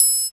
20 TRIANGLE.wav